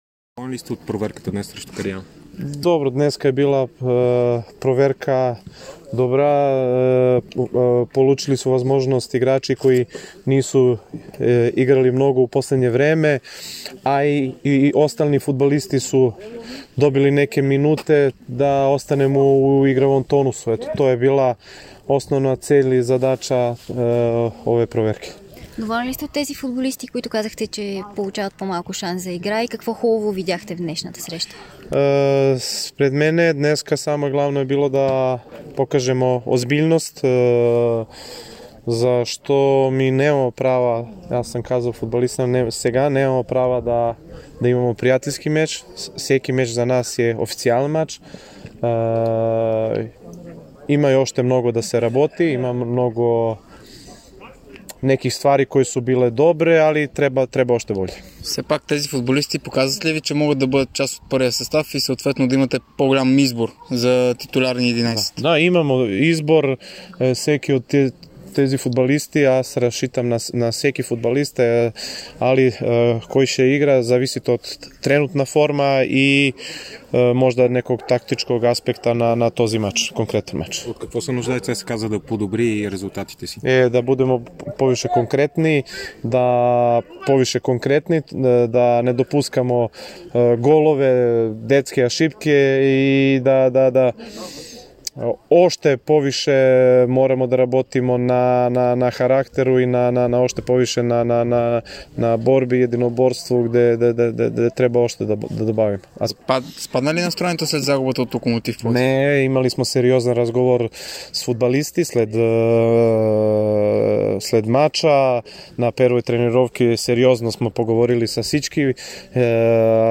Милош Крушчич говори пред медиите след победата на ЦСКА с 3:1 в контрола срещу втородивизионния Кариана Ерден.